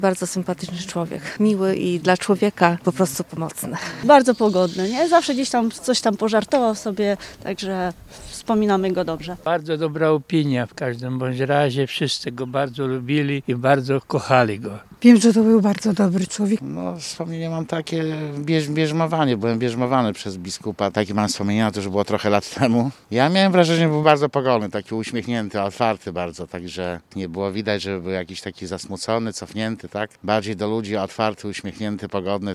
Zielonogórzanie wspominają biskupa Adama Dyczkowskiego.